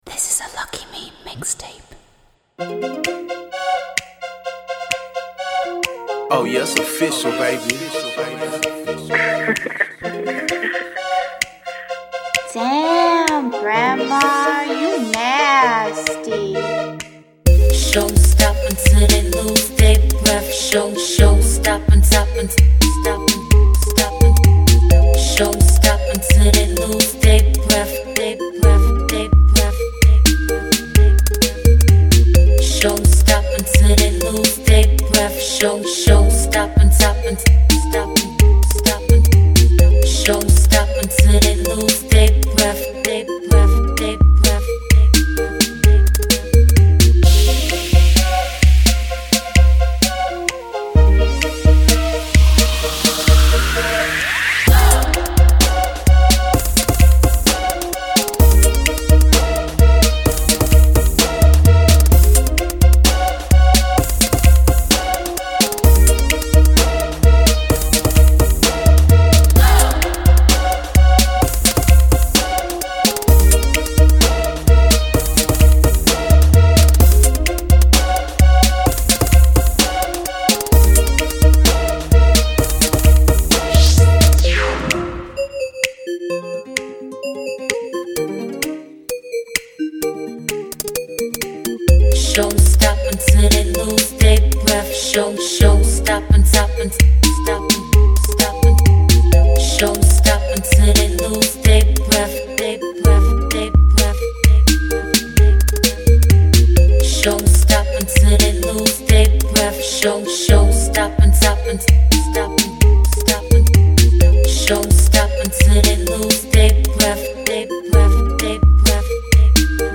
thirty minute mix